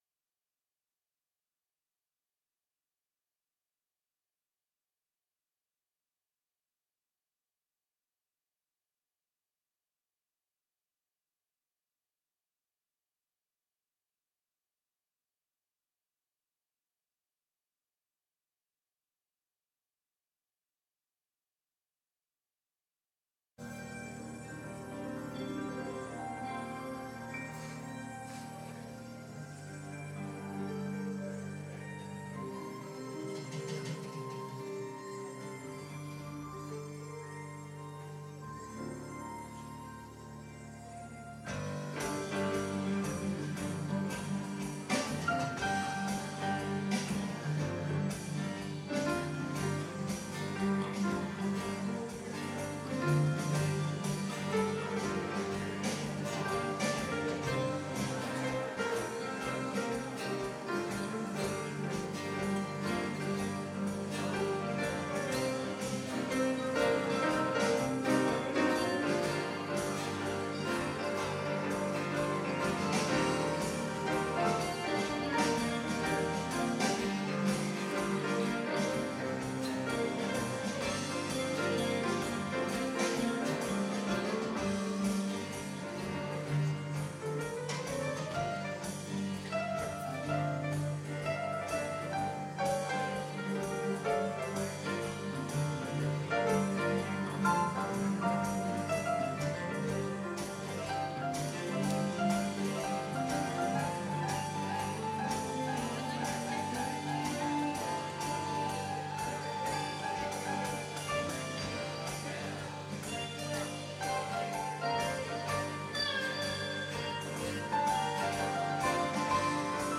Contemporary Worship Service Replays | Bethel International United Methodist Church